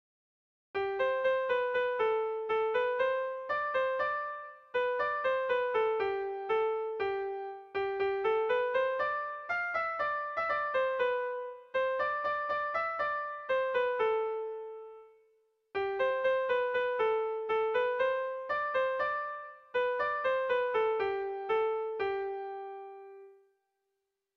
ABA